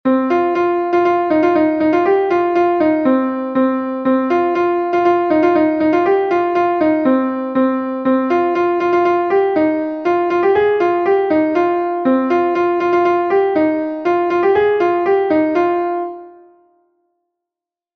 Gavotenn Leuelan is a Gavotte from Brittany